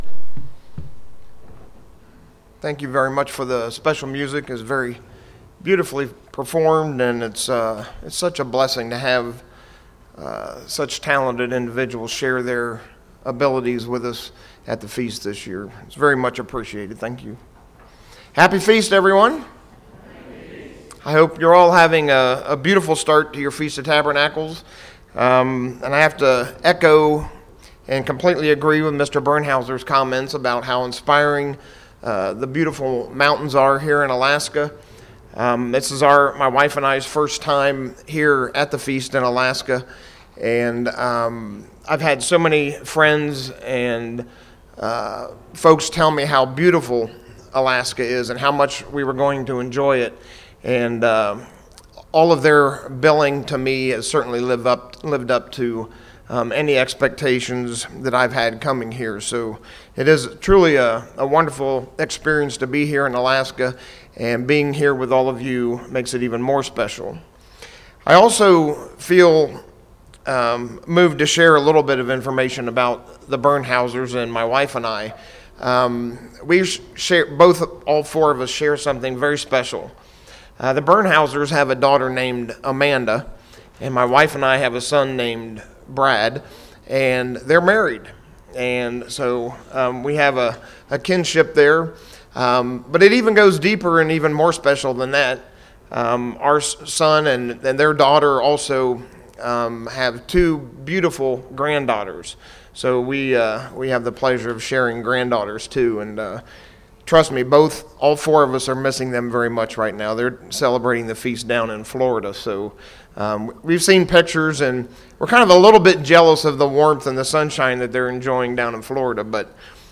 Anticipation, Joy and Thankfulness: What Event in Your Life Brought You Great Anticipation and Joy? This Sermon Will Focus on Three Very Important Aspects of the Feast: Anticipation, Joy and Thankfulness